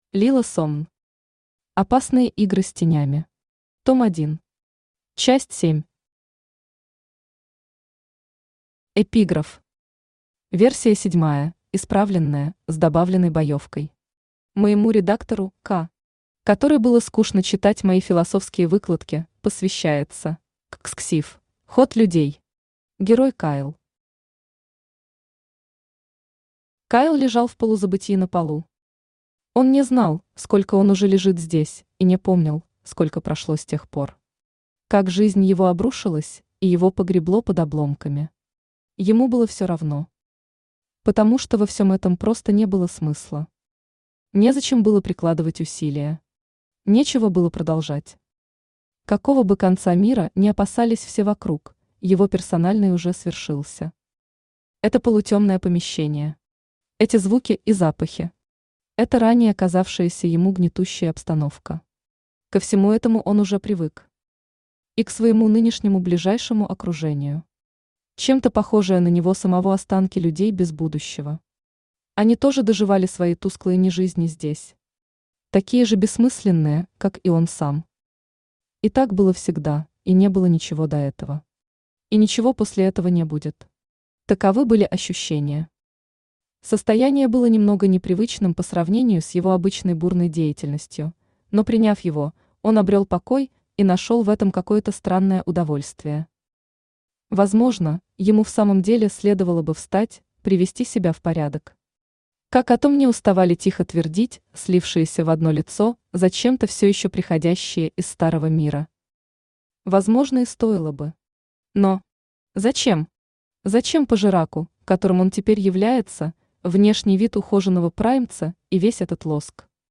Аудиокнига Опасные игры с тенями. Том 1. Часть 7 | Библиотека аудиокниг
Часть 7 Автор Лилла Сомн Читает аудиокнигу Авточтец ЛитРес.